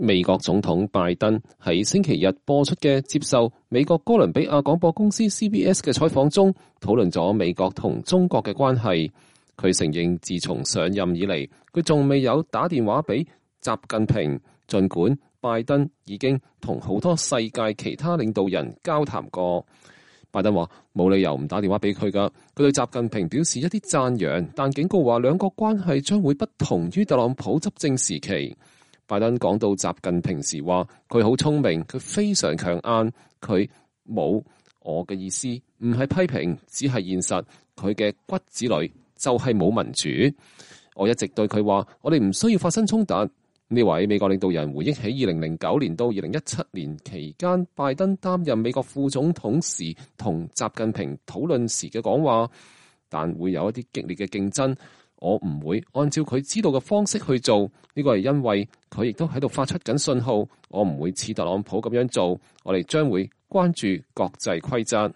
美國總統拜登在星期天播出的接受美國哥倫比亞廣播公司（CBS）的採訪中，討論了美國與中國的關係。